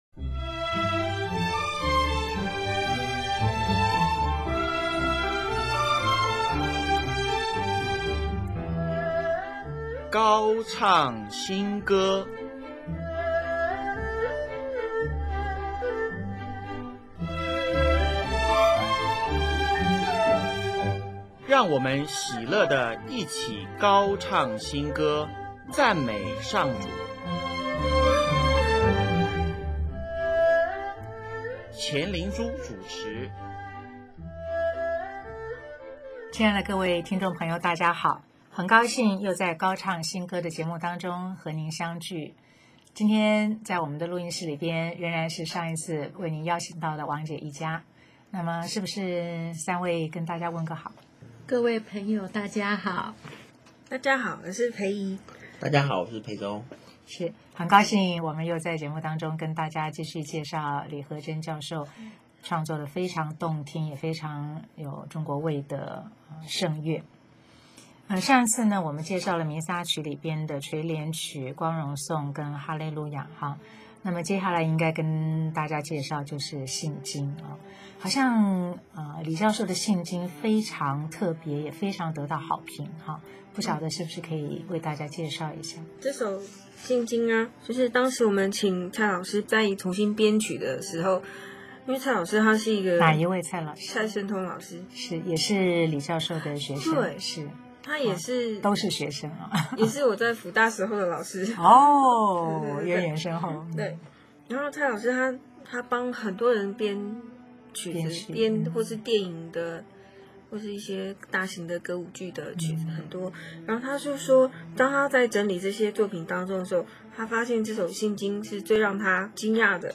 此外还播放“信德的奥蹟”、“羔羊讚”。
最后一首放的不是《羔羊赞》是《信经》的伴奏呀！